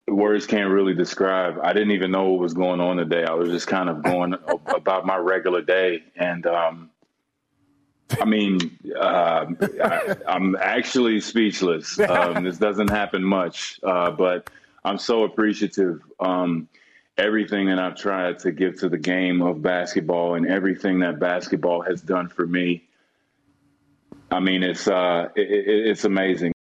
Bosh spoke after the announcement.